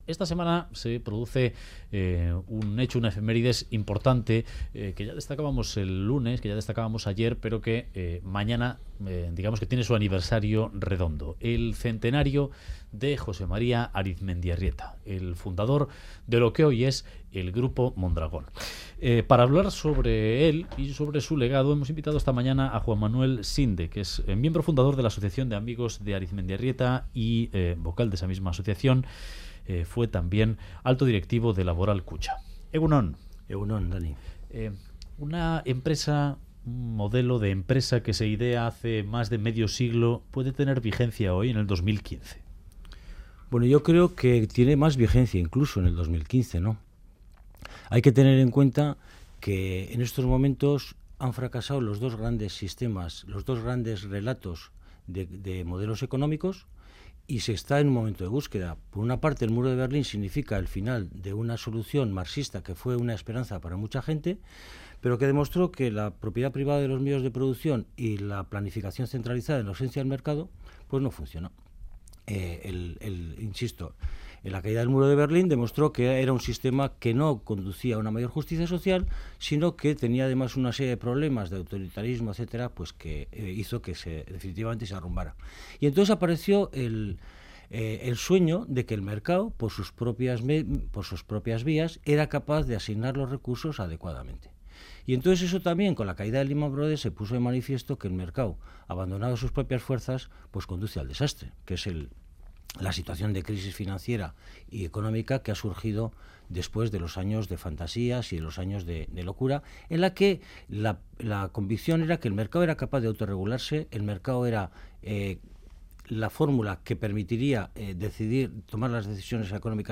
Radio Euskadi BOULEVARD